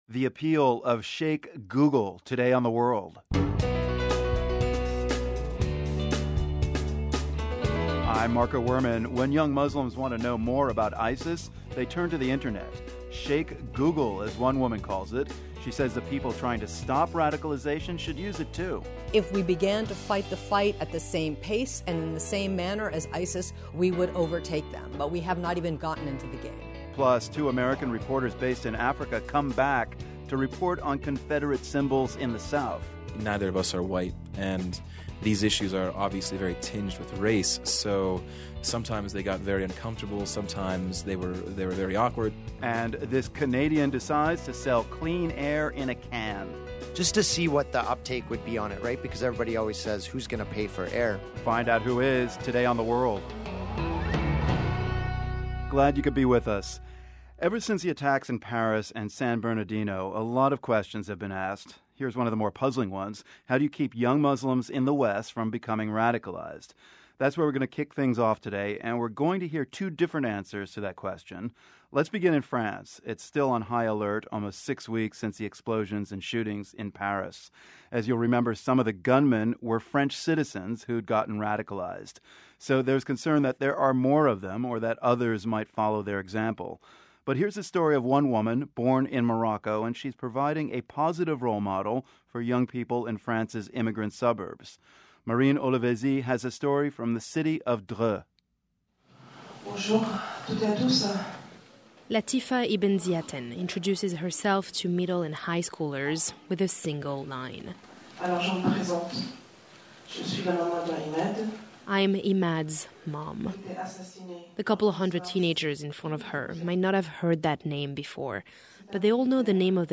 We go back to Puritan New England, where holiday fun was punishable by fines or even jail time. Also, we explore efforts to stem Islamic radicalization, both in Europe and the United States. Plus, we have a conversation on race in the United States with two Americans who normally report from Africa.